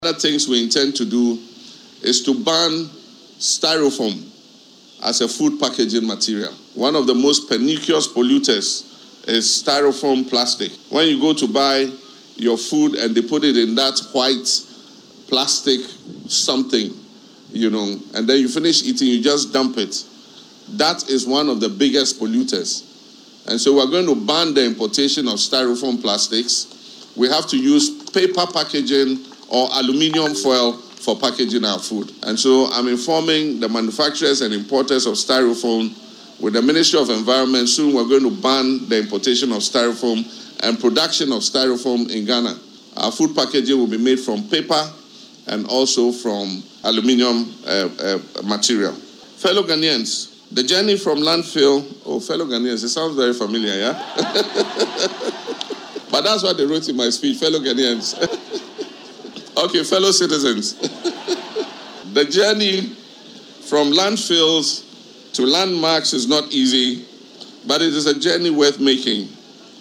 Speaking during World Environment Day celebrations at Kwabenya, Accra, the President explained that the move aims to preserve the country’s trees and promote the use of sustainable materials such as recycled plastic and metal.